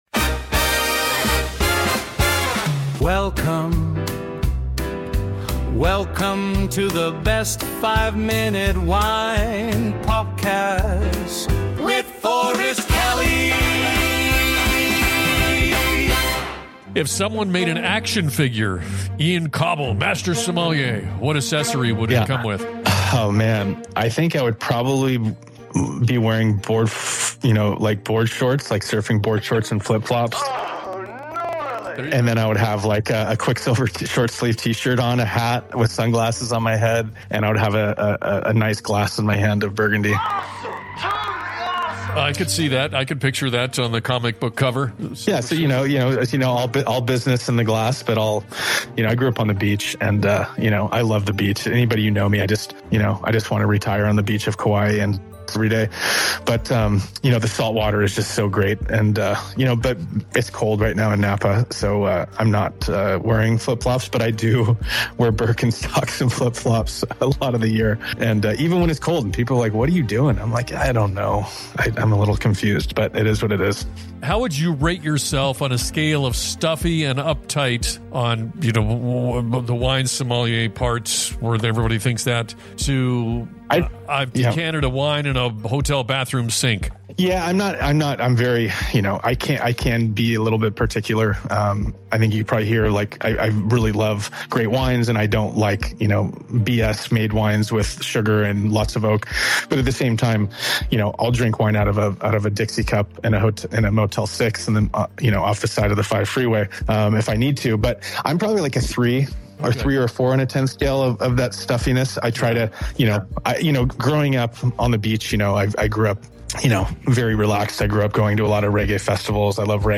a fun and relaxed conversation about wine culture and why wine should feel welcoming—not intimidating.